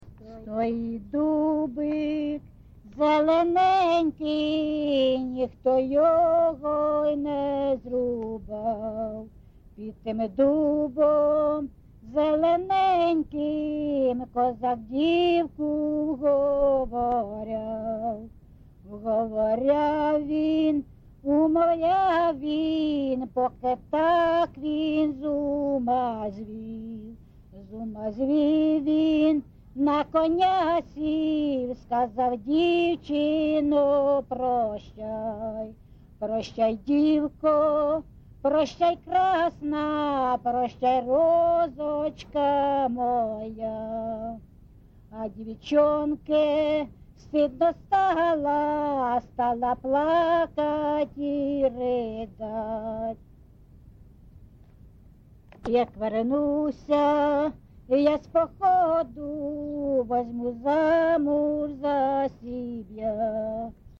ЖанрКозацькі, Солдатські
Місце записус. Андріївка, Великоновосілківський район, Донецька обл., Україна, Слобожанщина